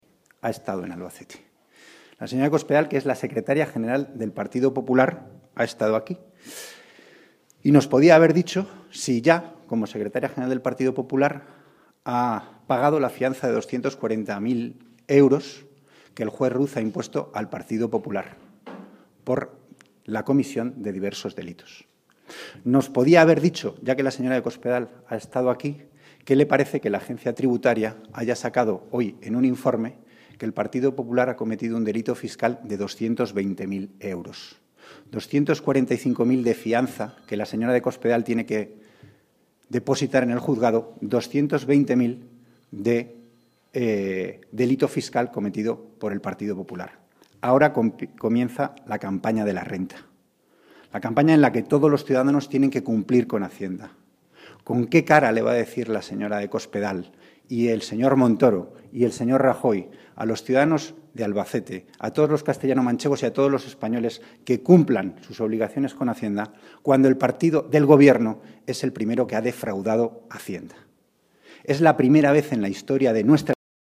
Durante la rueda de prensa ofrecida en la sede del PSOE de Albacete, Hernando subrayó que “hoy se da una buena casualidad y es que la señora Cospedal ha estado en Albacete, la señora De Cospedal es la secretaria general del PP y ha estado aquí y nos podía haber dicho si como secretaria general del PP, ¿si ha pagado la fianza de 245.000 euros que el juez Ruz ha impuesto al PP por la comisión de diversos delitos?, nos podía haber dicho ya que ha estado aquí, ¿qué le parece que la Agencia Tributaria haya sacado hoy en un Informe que el PP ha cometido un delito fiscal de 220.000 euros?.
Cortes de audio de la rueda de prensa